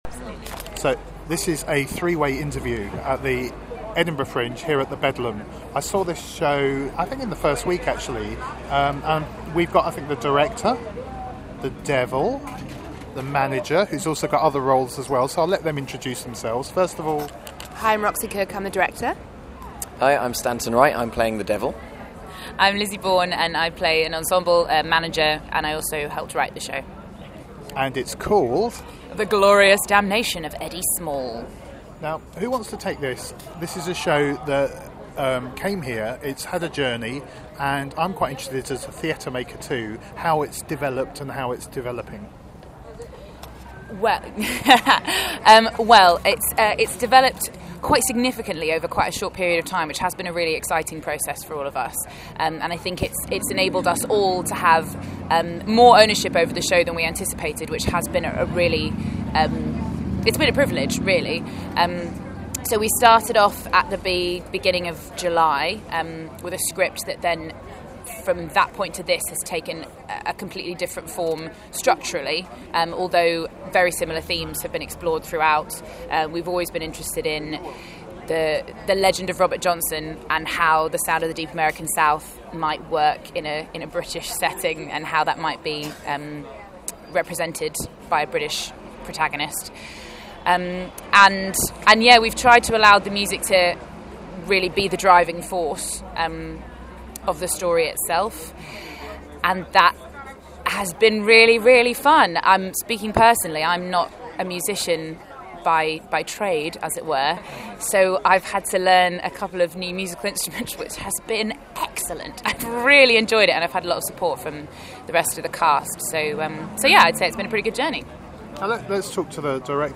listen-to-our-interview-with-the-team-from-the-glorious-damnation-of-eddie-small.mp3